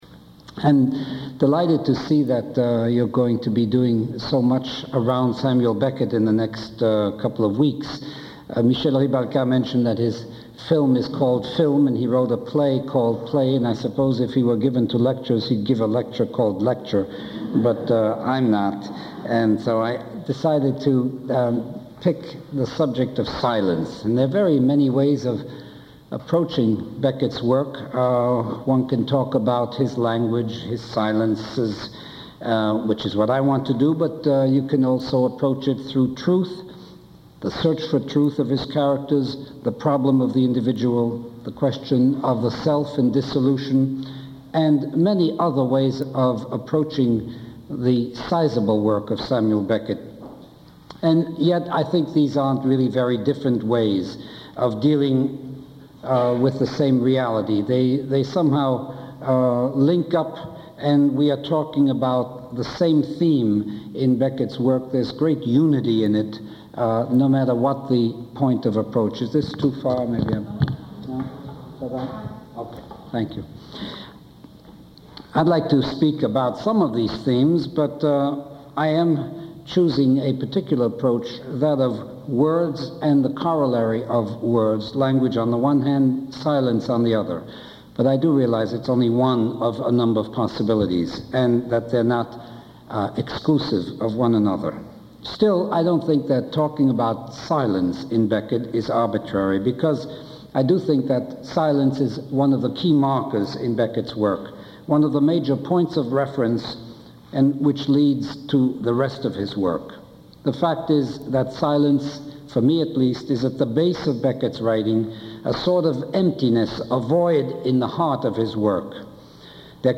Lecture Title